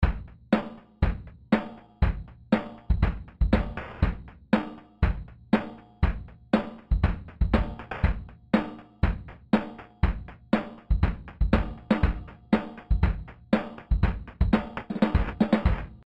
Verkürzt man die Grains, so kommt der ursprüngliche Beat wieder zum Vorschein:
… mit deutlichem Lo-Fi Charakter.